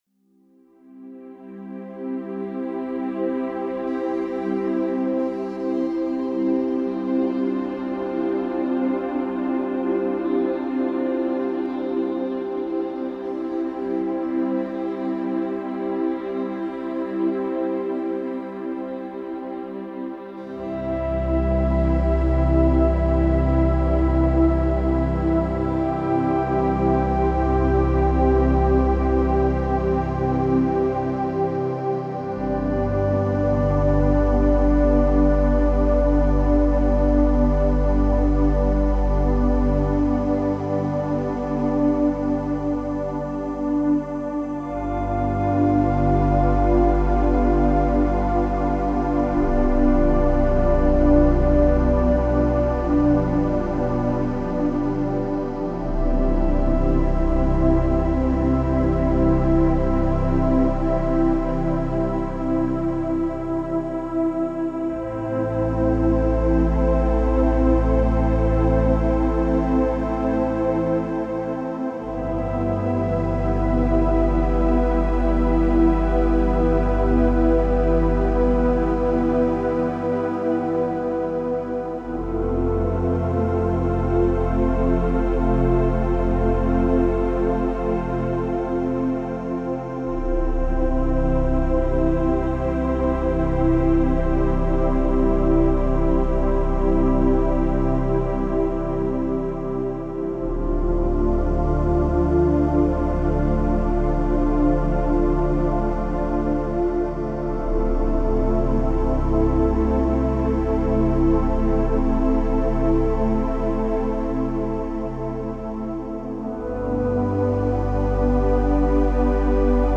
Calming music for stress relief, pre-sleep, and relaxation.